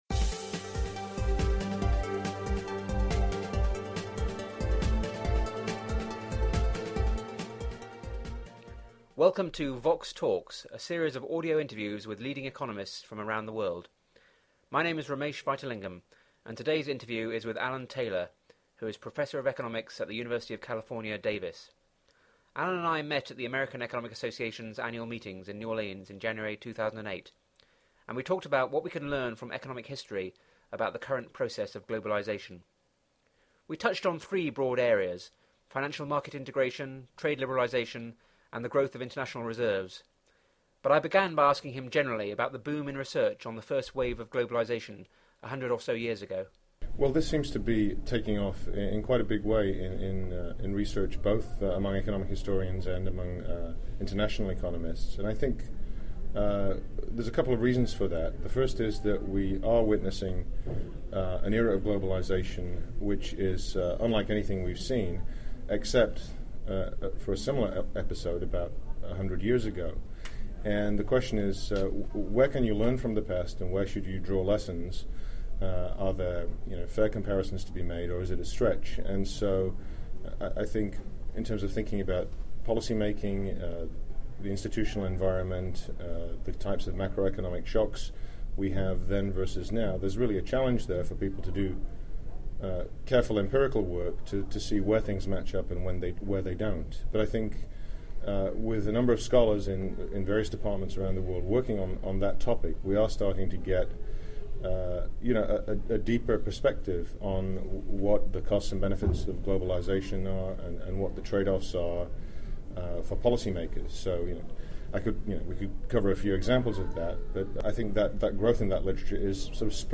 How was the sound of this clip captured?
The interview was recorded at the American Economic Association meetings in New Orleans in January 2008.